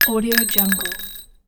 دانلود افکت صدای افتادن قطعه فلزی رو کاشی ۳
صدای افتادن فلز روی کاشی 3 یکی از همین الماس‌های صوتیه که با ظرافت کامل ضبط شده و هیچ‌گونه نویز یا افکتی مصنوعی توی اون نیست.
Sample rate 16-Bit Stereo, 44.1 kHz
Looped No